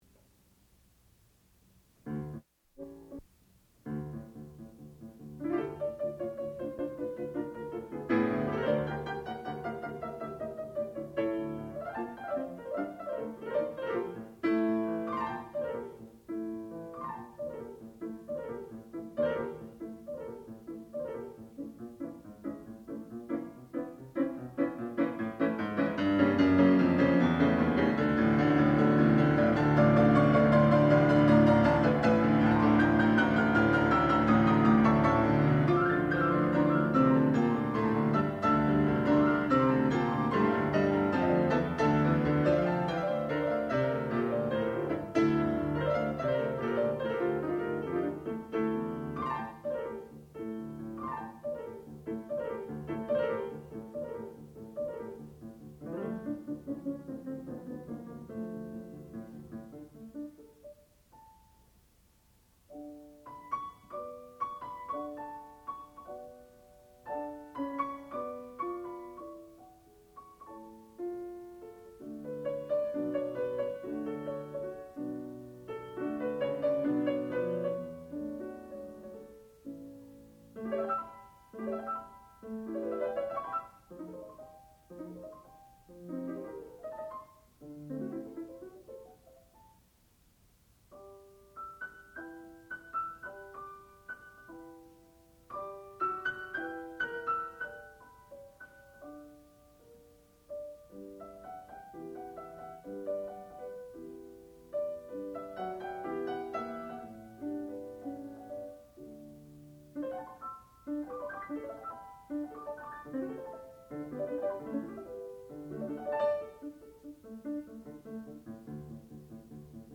sound recording-musical
classical music
Eva Knardahl, piano (performer).